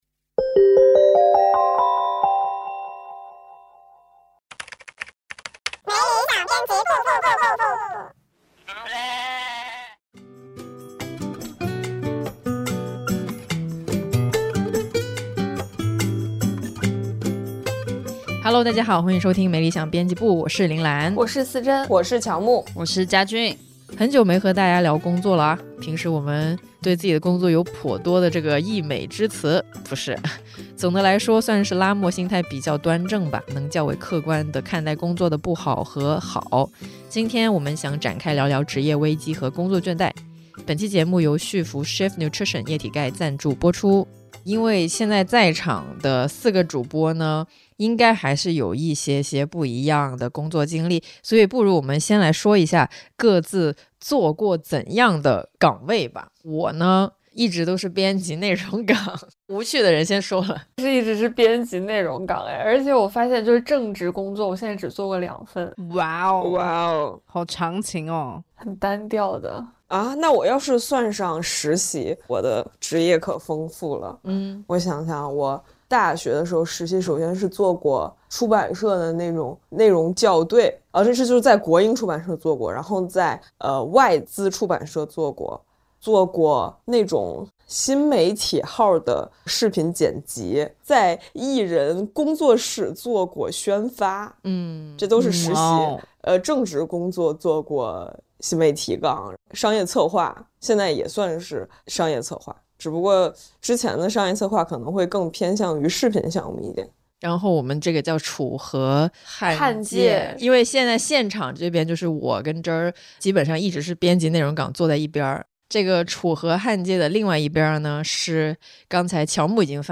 「没理想编辑部」是由「看理想」新媒体部出品的谈话类生活文化播客，a.k.a编辑们的午饭闲聊精选集，欢迎入座。